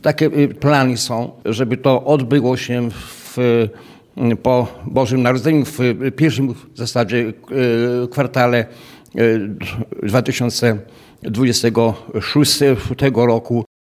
Przewodniczący rady miasta Wiesław Grzymała podkreślił, że święty Wawrzyniec zostanie ustanowiony patronem na uroczystej sesji rady.